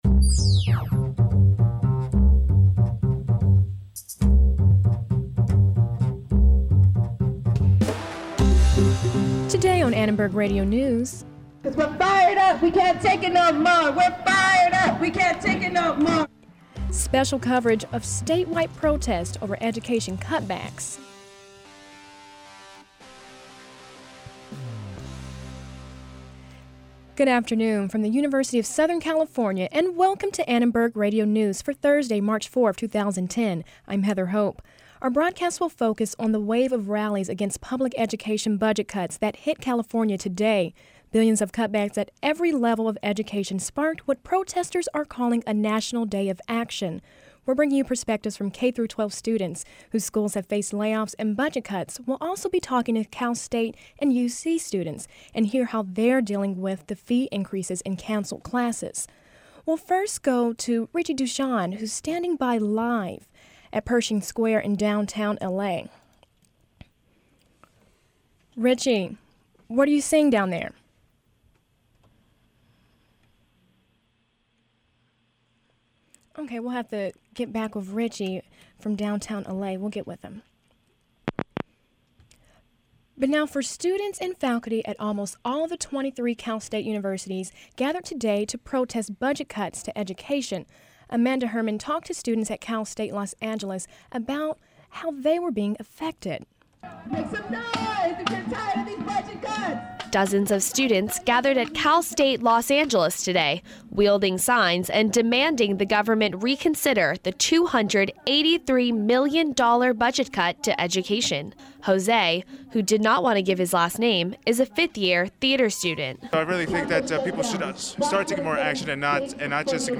We bring you reporters live from Pershing Square, where thousands of students, teachers, and faculty from every level of education are protesting against the billions in state budgets cuts for public education.